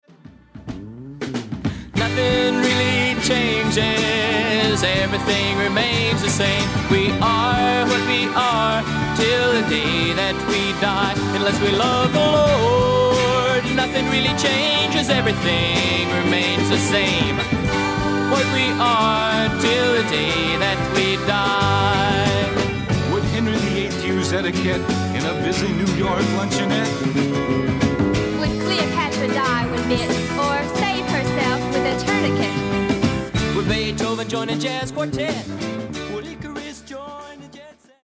SOFT ROCK / GARAGE